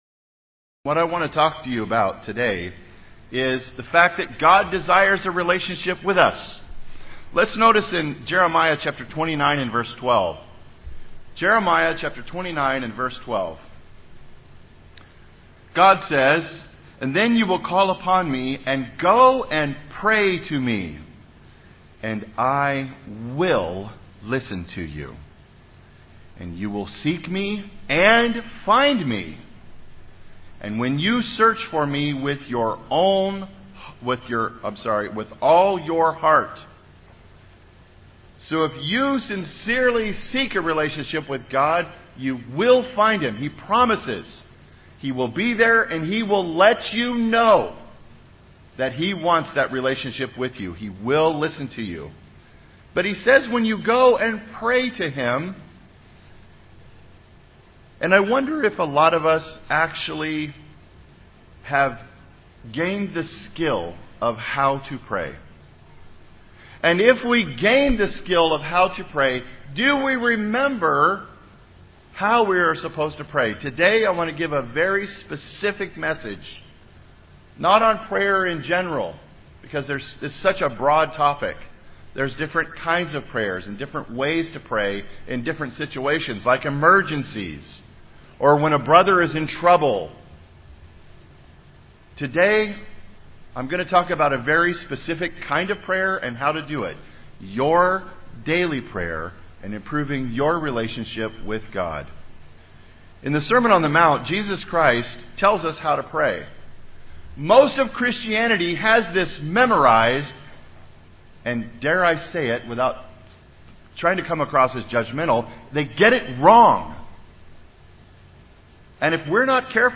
How can we build our relationship with God? This sermon helps us learn how to build our relationship with God through the proper use of prayer.